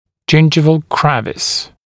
[‘ʤɪnʤɪvəl ‘krevɪs] [ʤɪn’ʤaɪvəl][‘джиндживэл ‘крэвис] [джин’джайвэл]десневая борозда